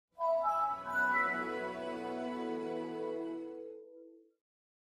Звуки приветствия Windows